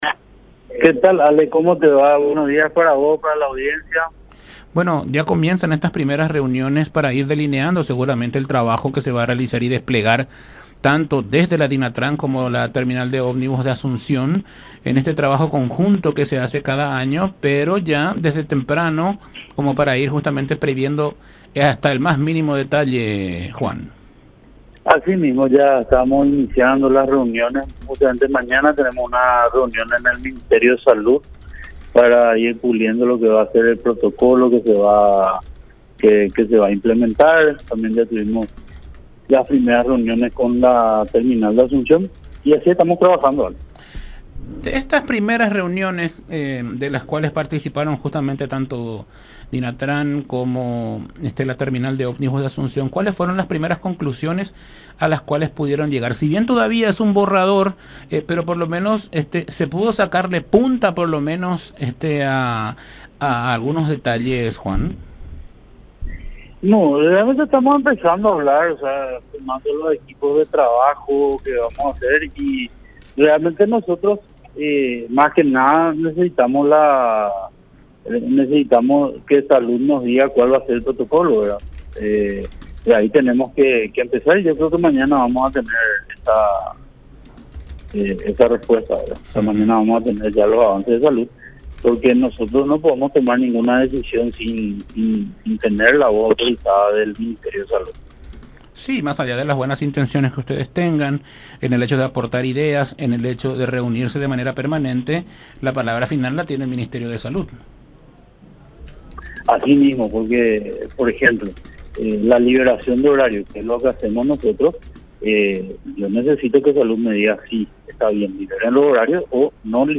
“Mañana viernes tenemos una reunión con el Ministerio de Salud para ir puliendo los protocolos a ser establecidos para fin de año. También ya tuvimos las primeras reuniones con autoridades de la Terminal de Ómnibus de Asunción. Seguimos trabajando en ese sentido”, dijo Vidal en conversación con Todas Las Voces por La Unión.